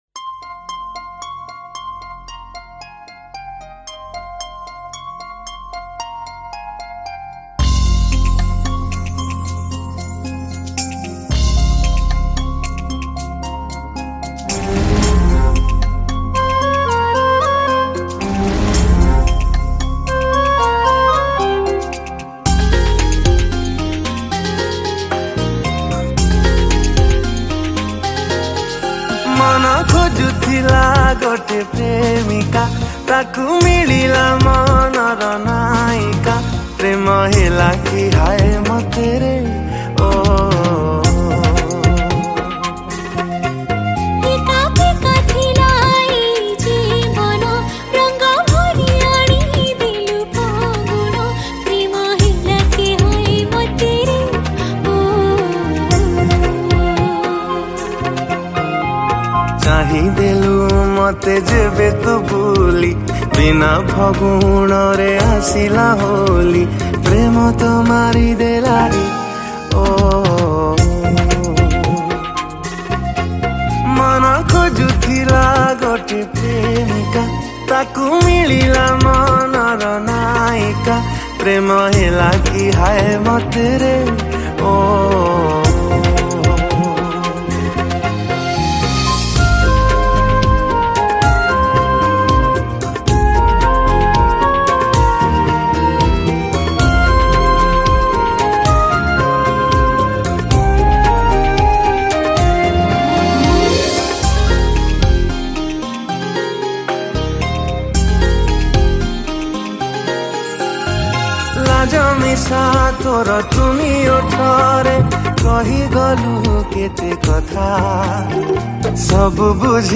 Sad Romantic Songs